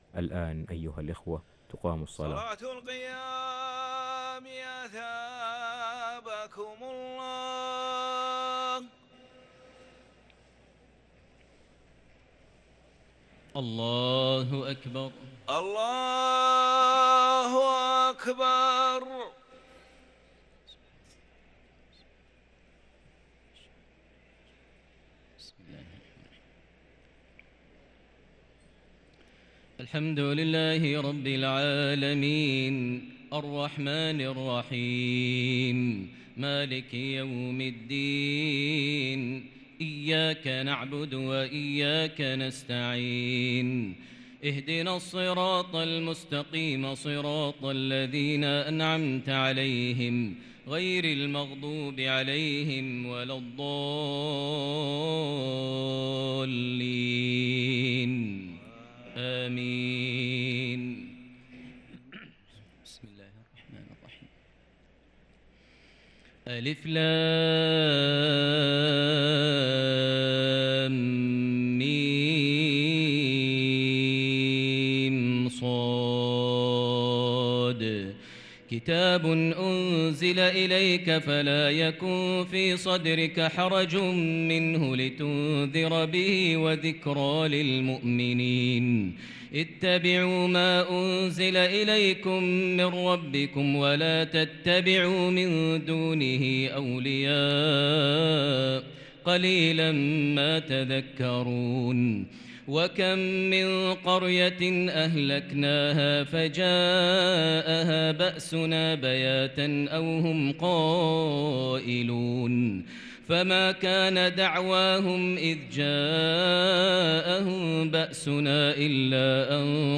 تراويح ليلة 11 رمضان 1443هـ| سورة الأعراف (1-79) |Taraweeh 11st night Ramadan 1443H Surah Al-Araf 1-79 > تراويح الحرم المكي عام 1443 🕋 > التراويح - تلاوات الحرمين